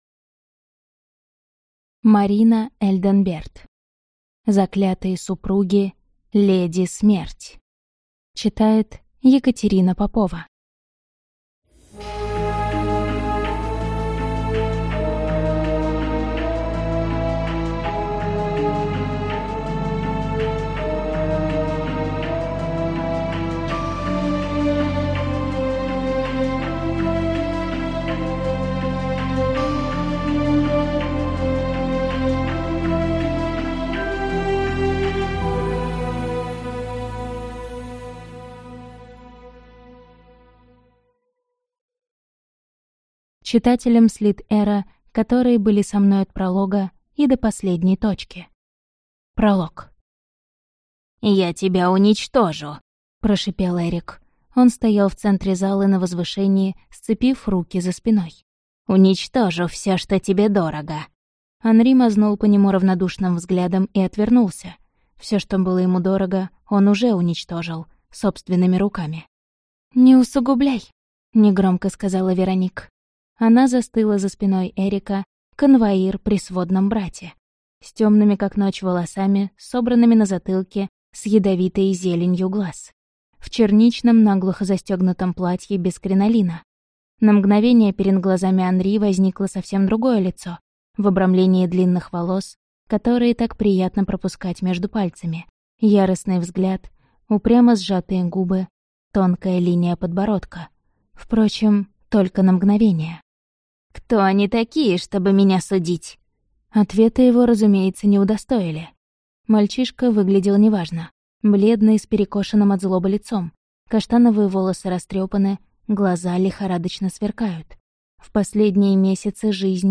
Аудиокнига Заклятые супруги.